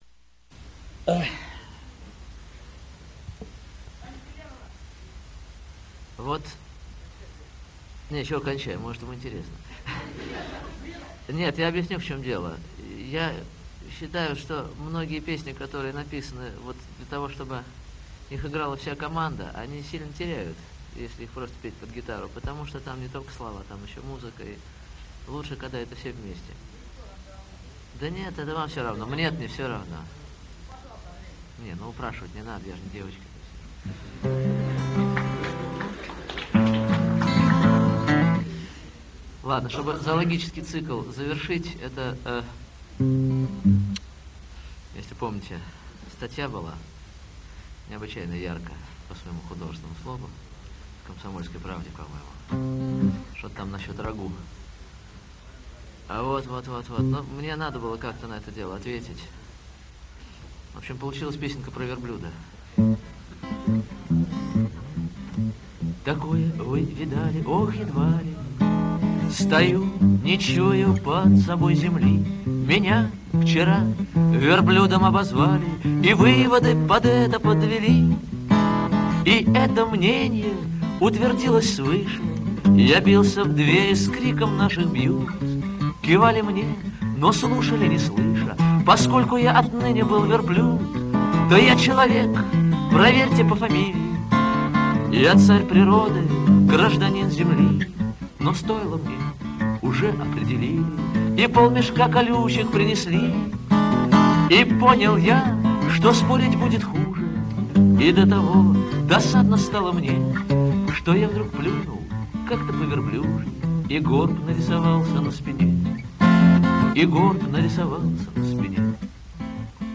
Сольный концерт
в московской школе